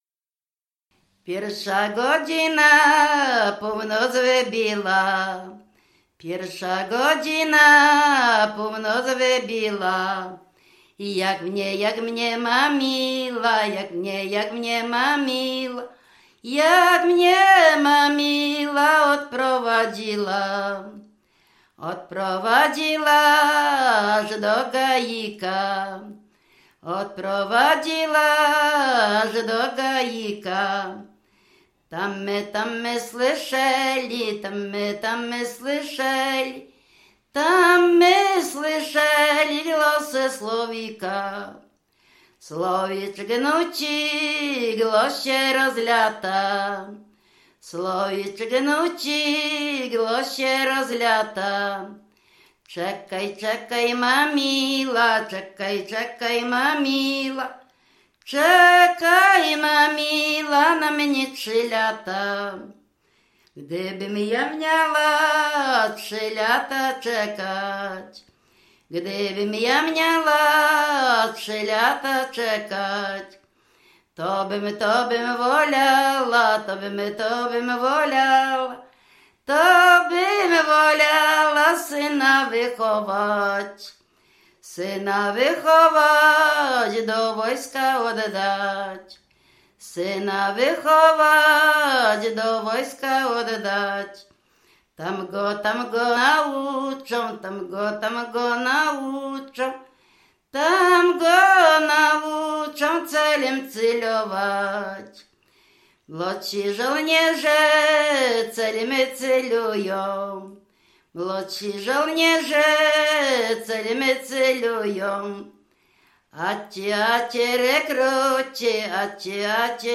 W wymowie Ł wymawiane jako przedniojęzykowo-zębowe;
rekruckie wojenkowe